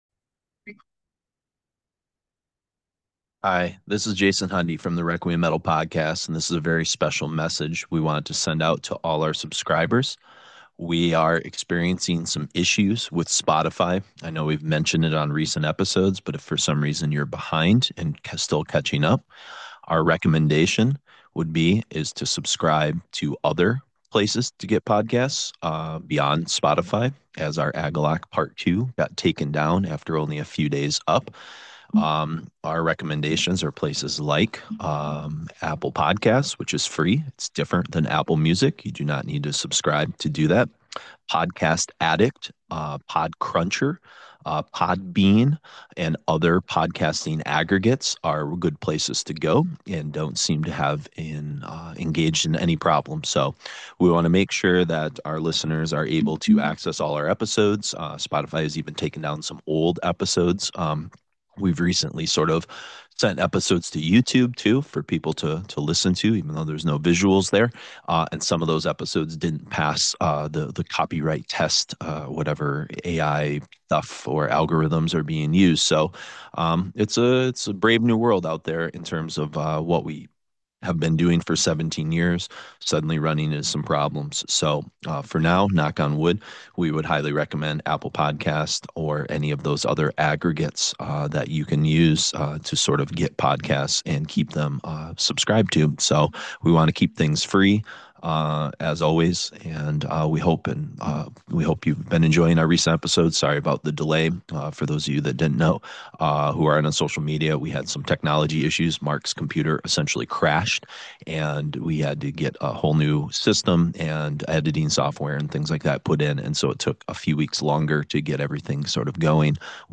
A history teacher and an artist talk about the heaviest of metal and adjacent genres.
Trailer: